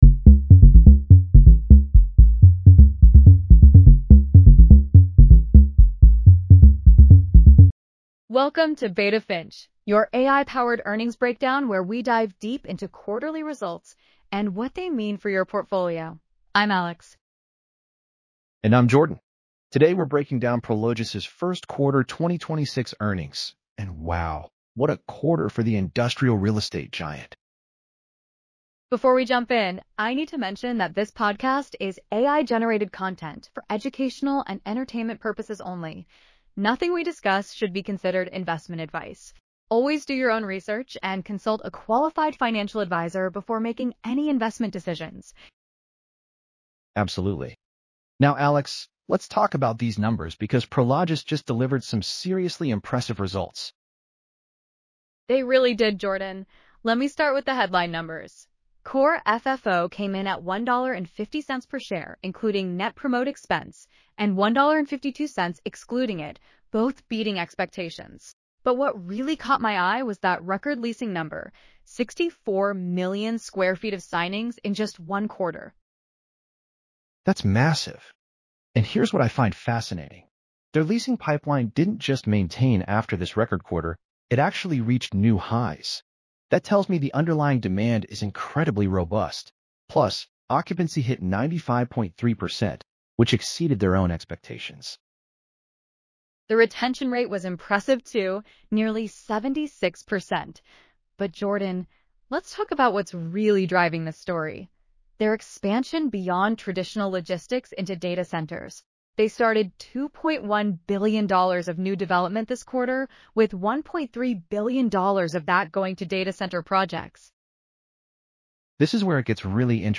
This is AI-generated content for educational purposes only.